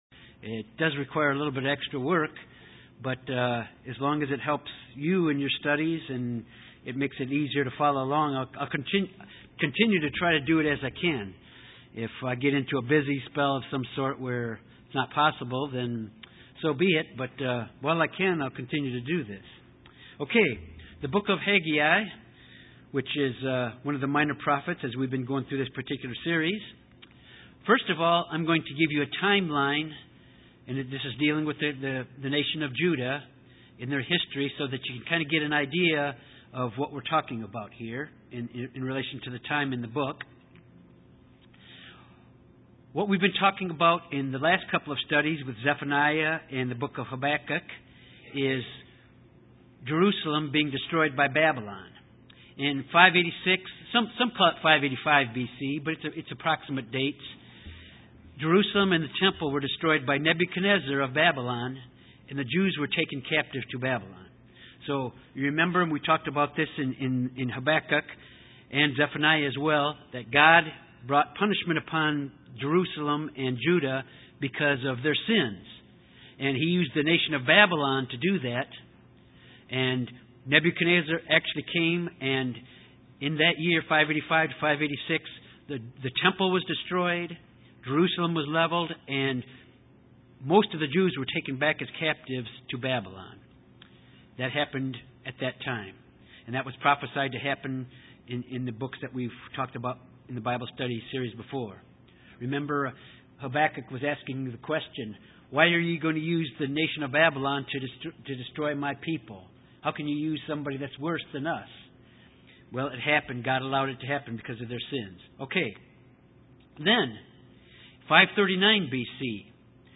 This Bible Study of Haggai deals with the rebuilding of the temple in Jerusalem.
Given in Little Rock, AR